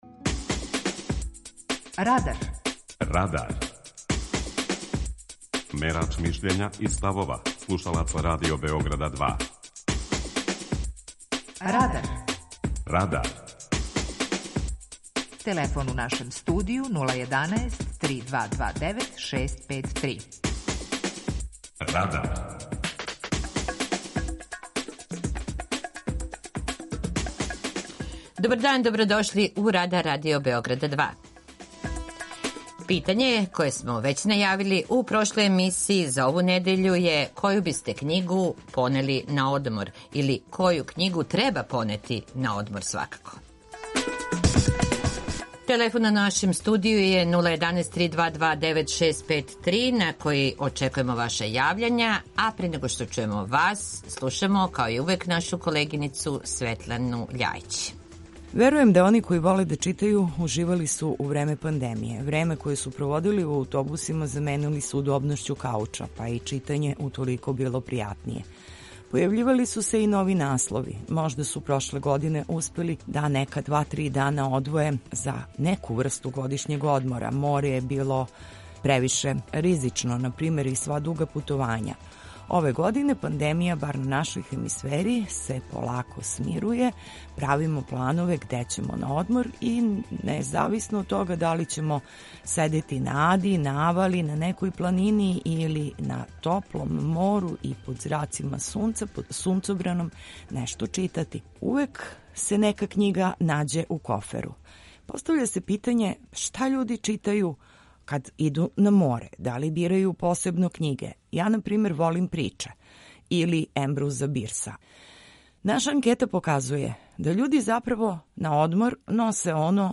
Питање Радара је: Коју књигу ћете понети на одмор? преузми : 19.09 MB Радар Autor: Група аутора У емисији „Радар", гости и слушаоци разговарају о актуелним темама из друштвеног и културног живота.